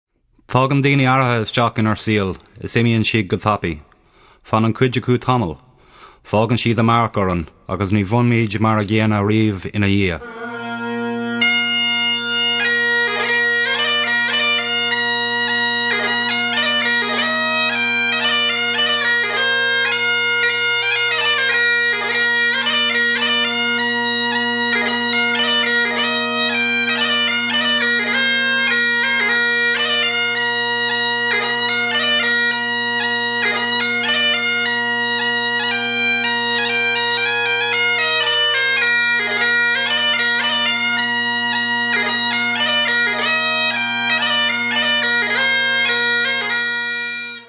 He would love to play at your event and add the traditional sound of the bagpipes to make your day special.
Charleston-Bagpiper-1-Scotland-The-Brave.mp3